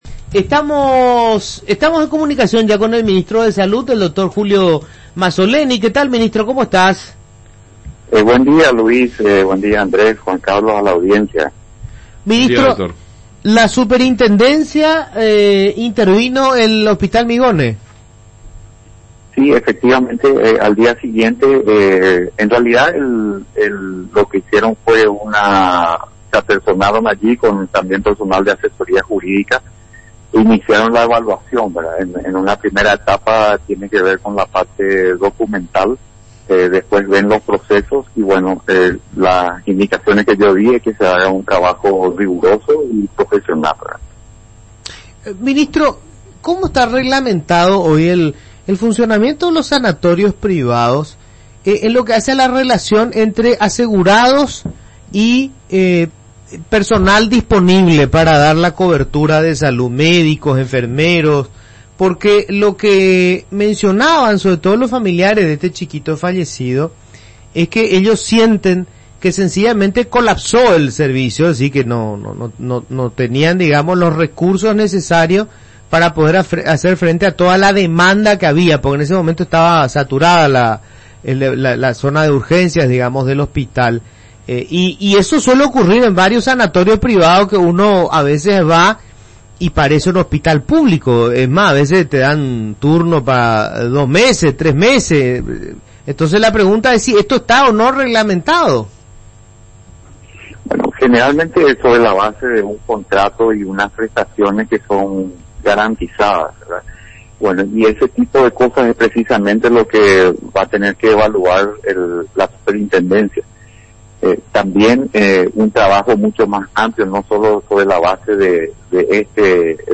julio-mazzoleni-ministro-de-salud-intervención-al-migone.mp3